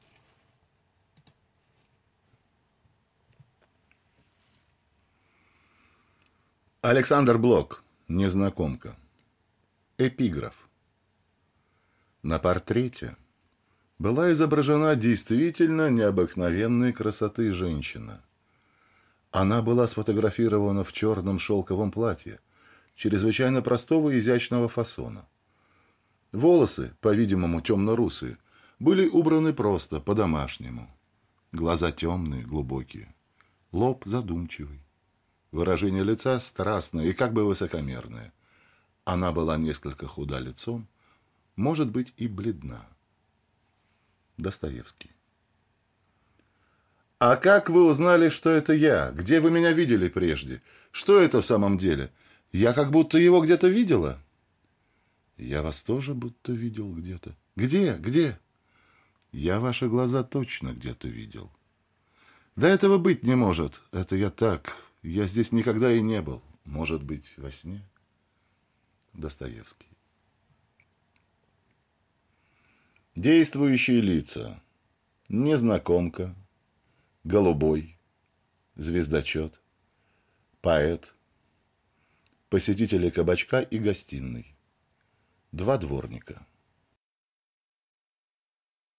Аудиокнига Незнакомка | Библиотека аудиокниг